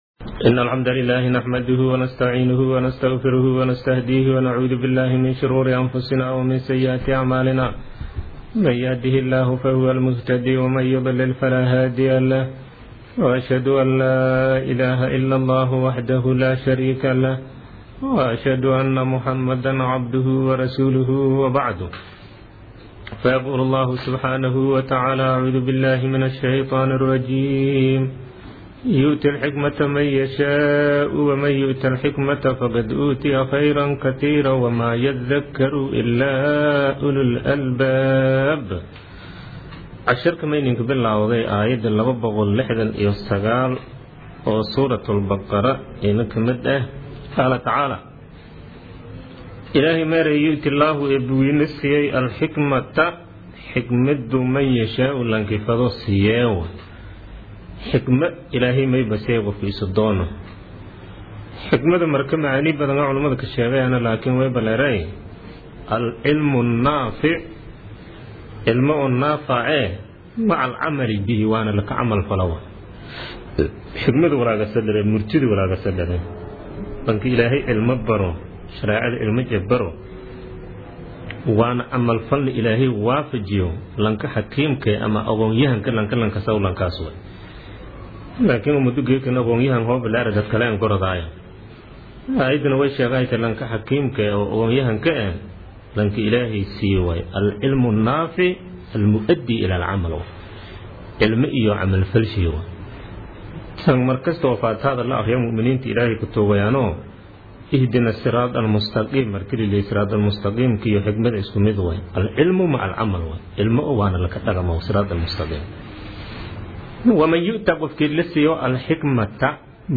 Casharka Tafsiirka Maay 36aad